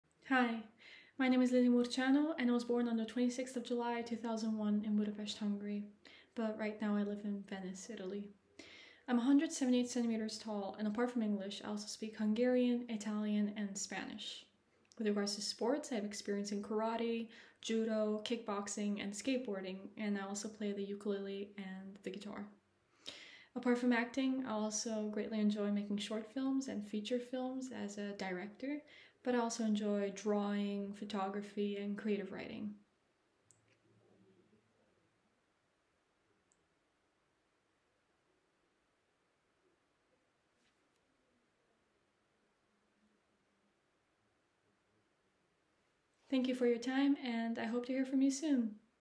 American Accent